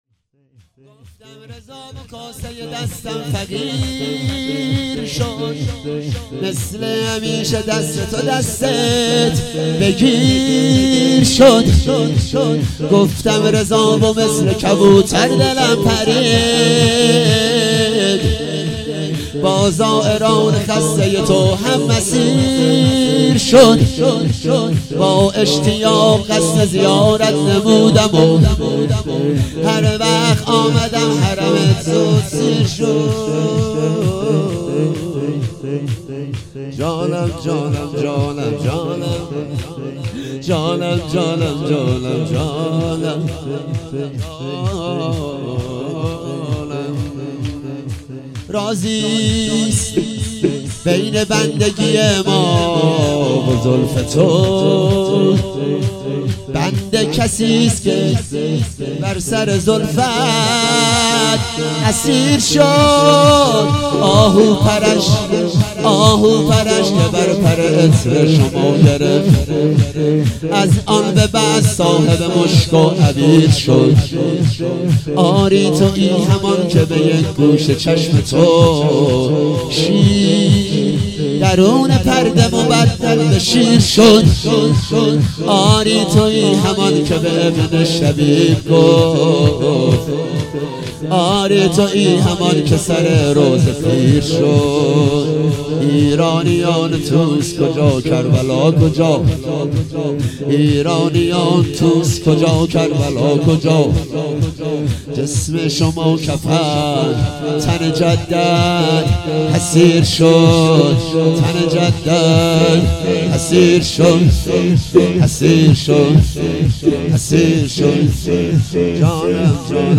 دهه آخر صفر - شب دوم - شور - گفتم رضا و کاسه ی دستم فقیر شد